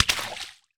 water_splash_small_item_05.wav